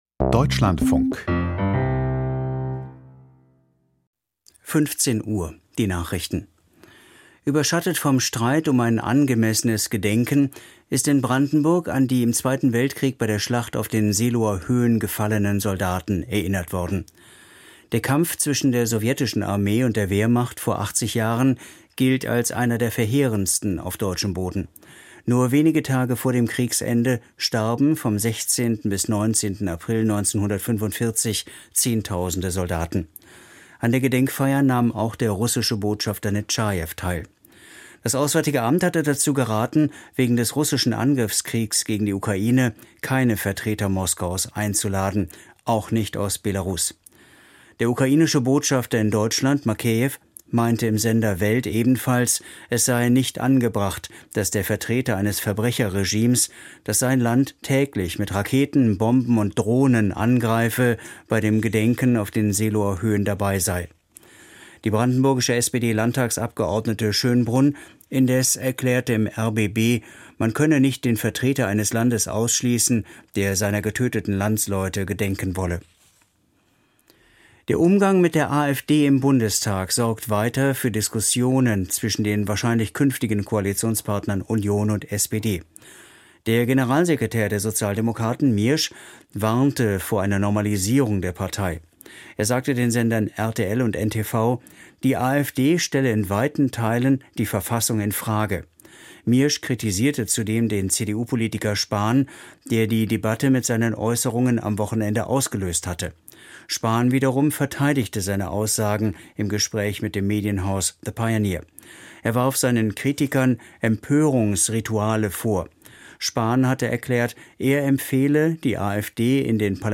Die Deutschlandfunk-Nachrichten vom 16.04.2025, 15:00 Uhr